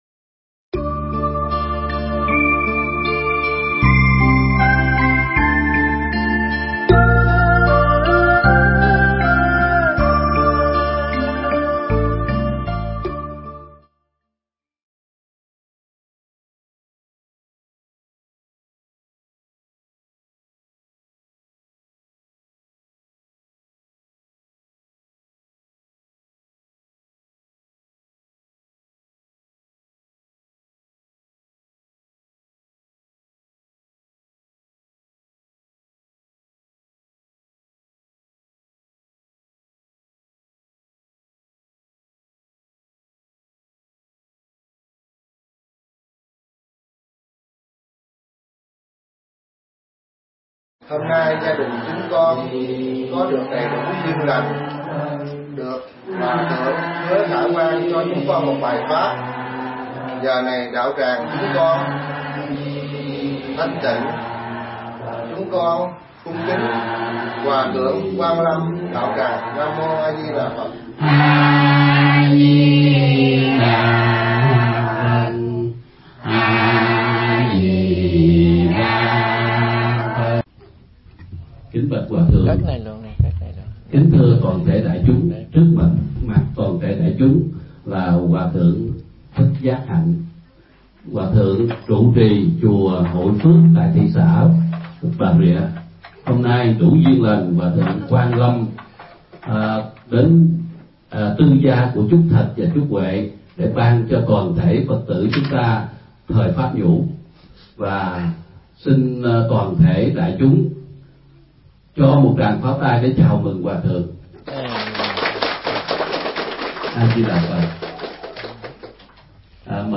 Nghe Mp3 thuyết pháp Nhân Thừa Phật Giáo